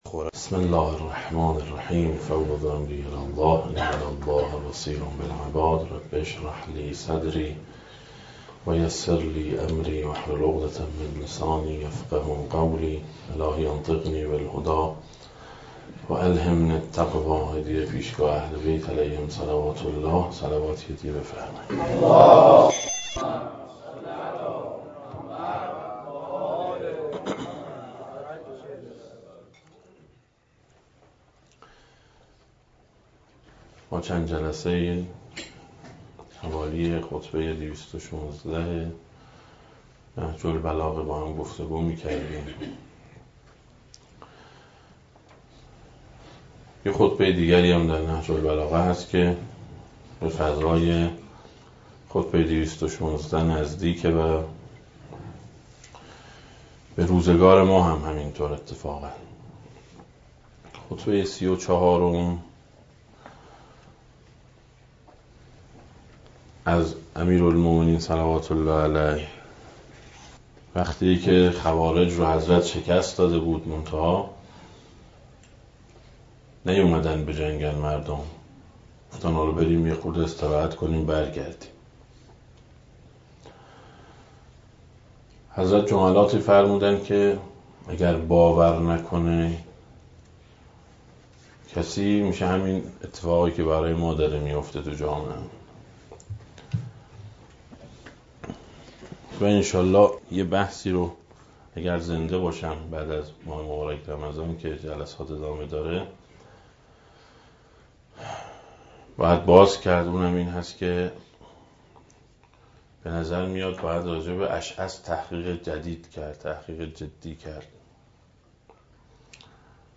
دسته: امیرالمومنین علیه السلام, درآمدی بر نهج البلاغه, سخنرانی ها